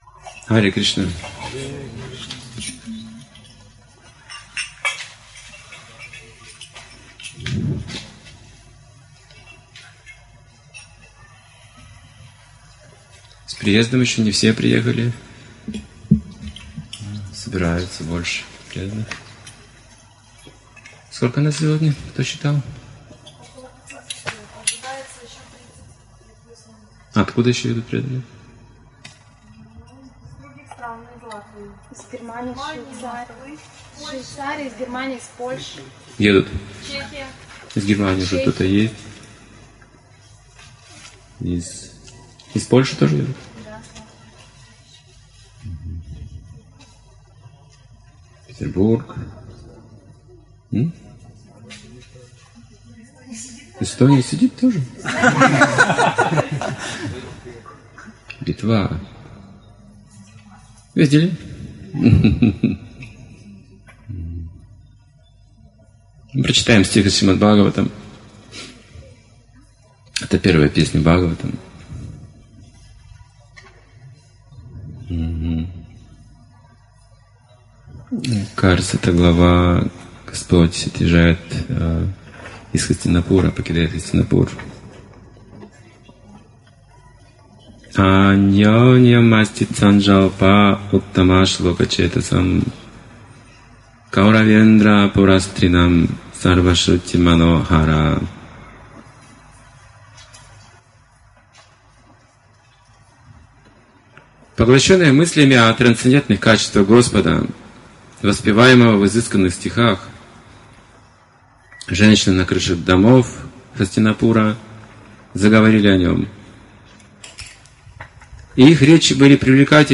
Шримад-Бхагаватам 1.10.20, Фестиваль "Святоустье"